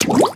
CharaMario_Splatoon_ToHuman.wav